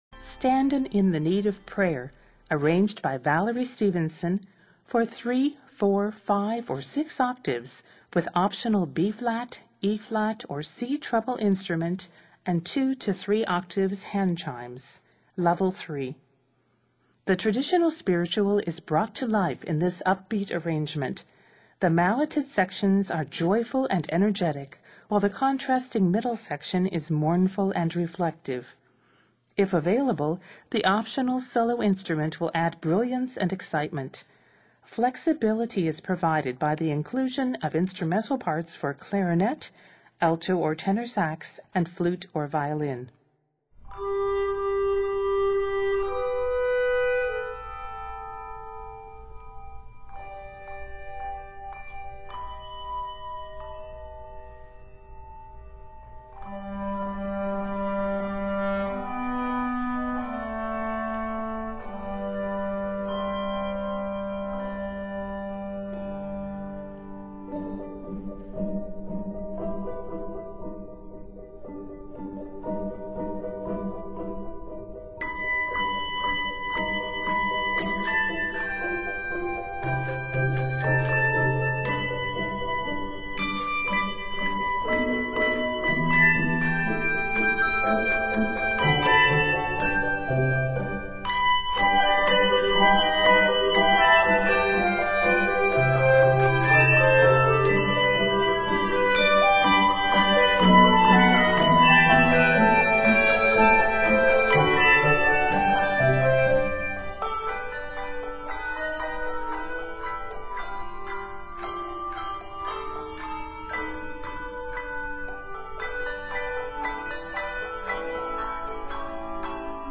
Set in G Major, measures total 65.